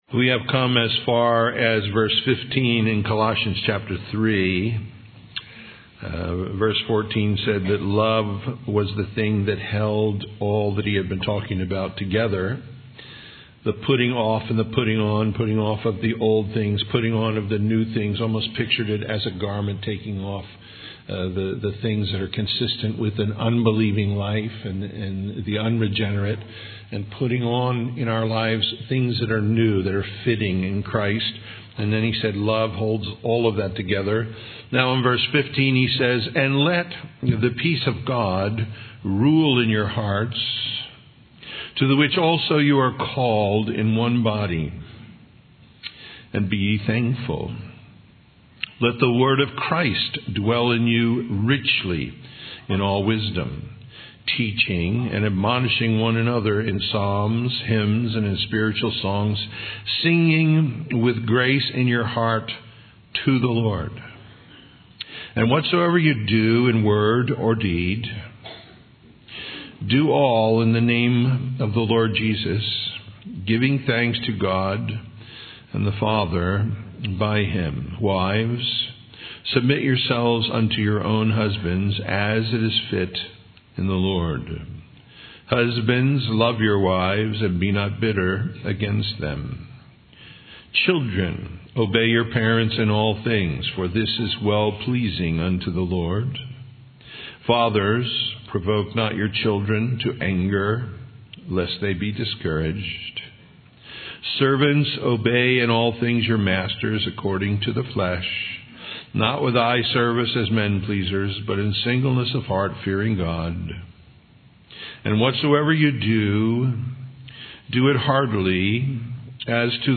Colossians 3:15-4:1 Practical Matters Listen Download Original Teaching Email Feedback 3 4 And let the peace of God rule in your hearts, to the which also ye are called in one body; and be ye thankful.